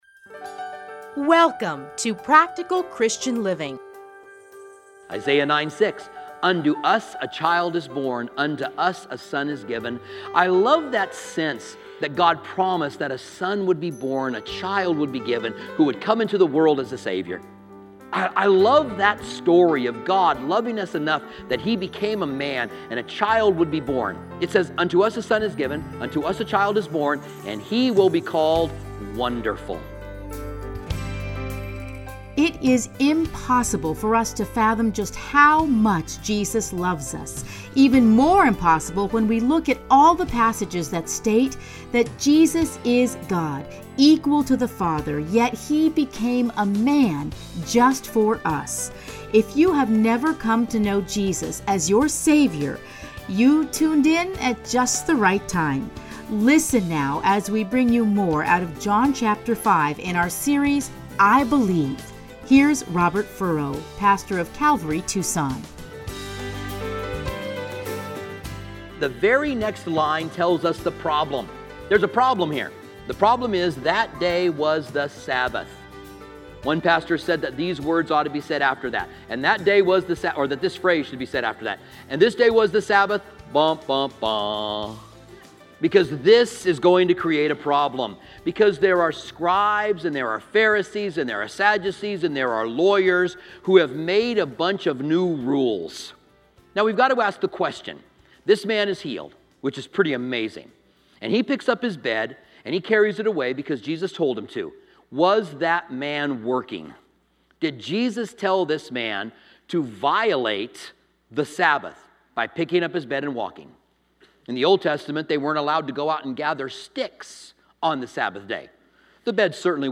teachings are edited into 30-minute radio programs